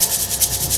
Shaker FX 02.wav